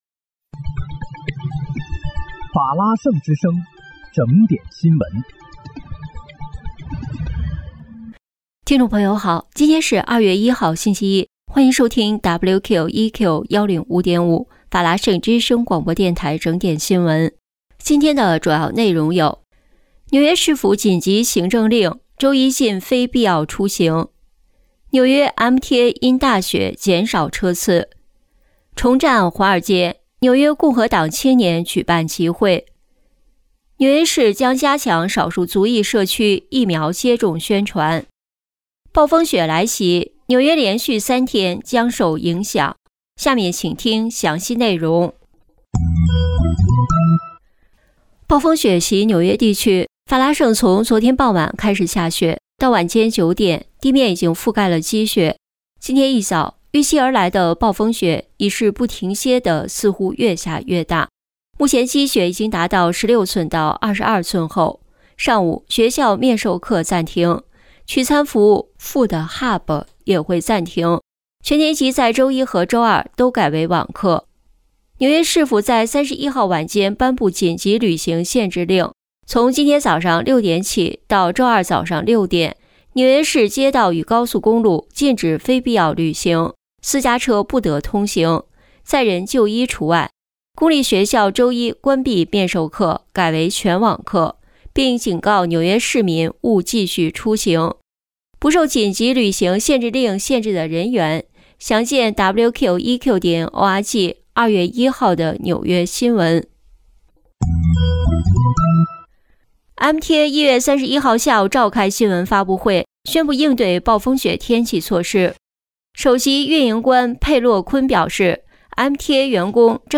2月1日（星期一）纽约整点新闻